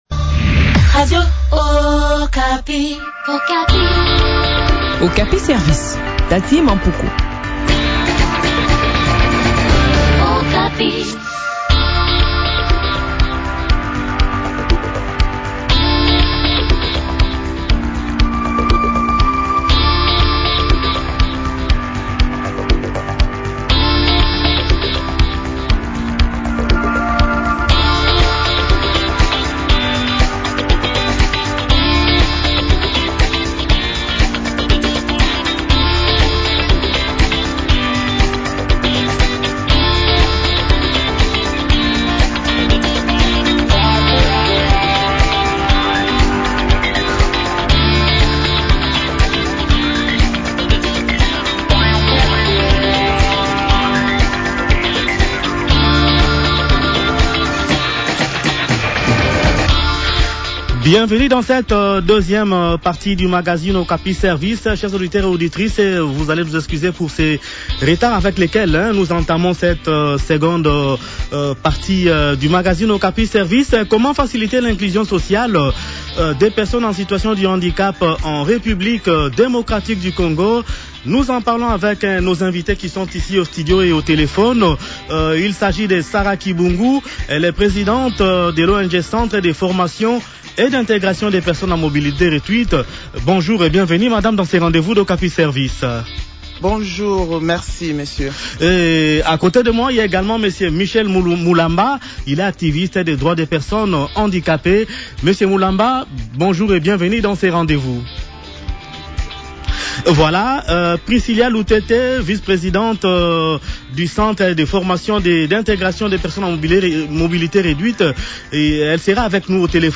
activiste des droits de personnes handicapées a également pris part à cette interview.